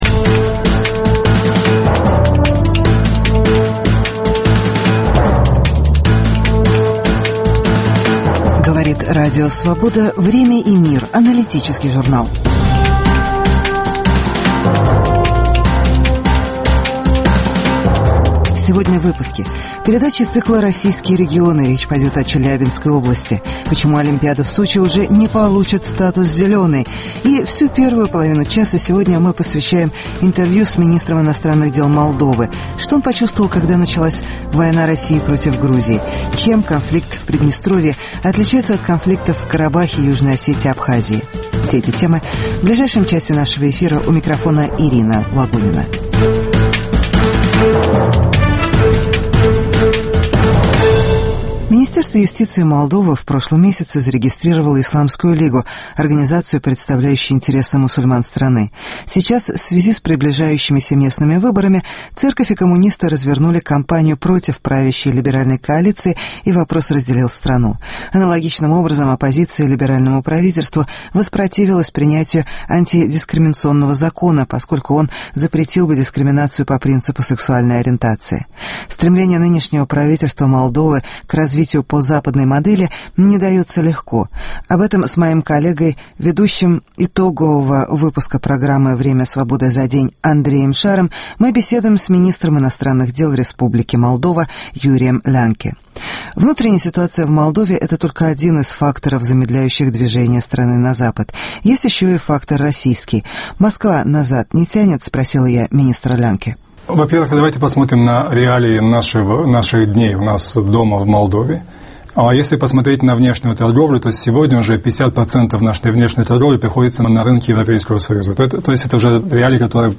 Интервью с министром иностранных дел Молдовы. Что он почувствовал, когда началась война России против Грузии?